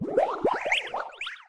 Bubble.wav